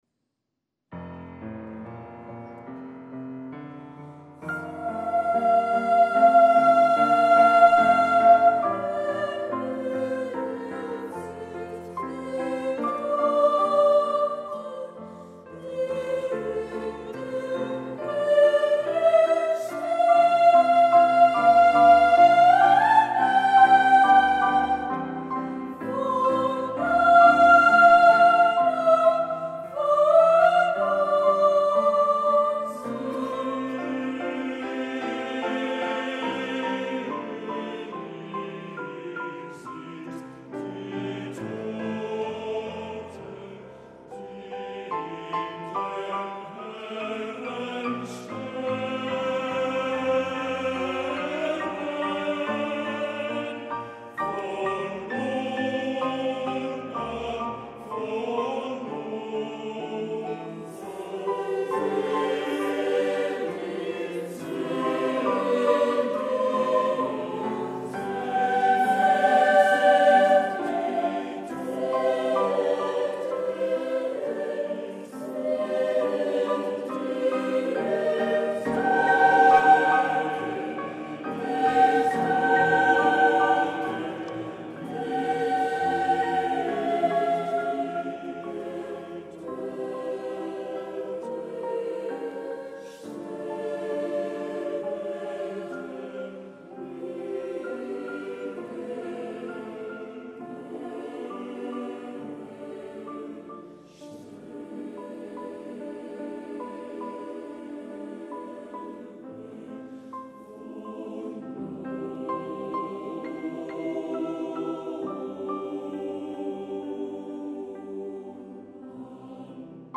Auditori Illa Diagonal 16 d’abril de 2016.
Sense estar una mica boig és impossible entendre com el concert de primavera del Cor Vivaldi tenia com a única obra del programa l’integral del Ein Deutsches Requiem op 45 de Johannes Brahms, una d’aquelles obres que posa a prova a qualsevol cor professional, ja que els amateurs no gosen enfrontar-se amb obres d’aquesta densitat i dificultat, i fan bé.